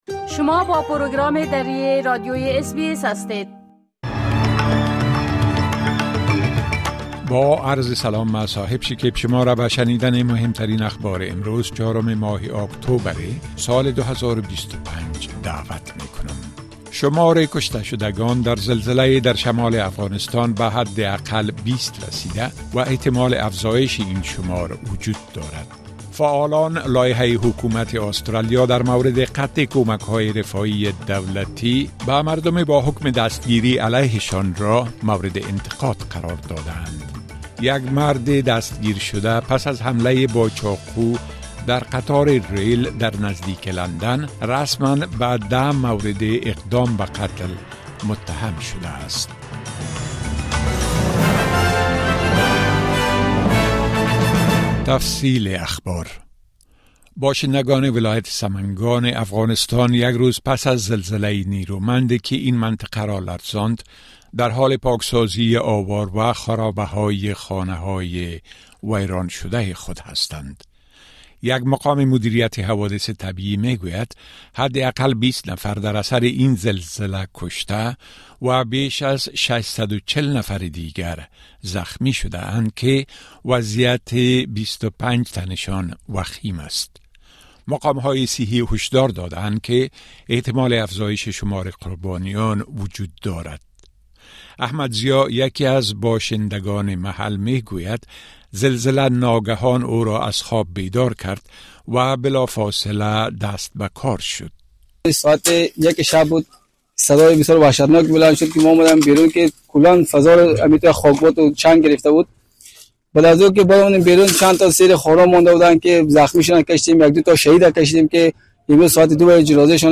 مهمترين اخبار روز از بخش درى راديوى اس بى اس